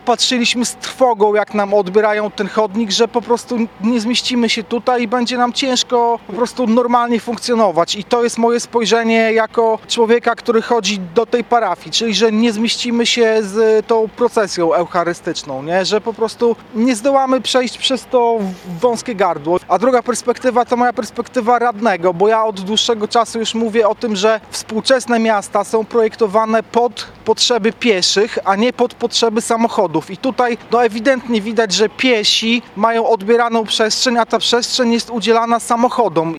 Zwężenia trotuaru nie chce również Robert Wesołowski, radny ełcki.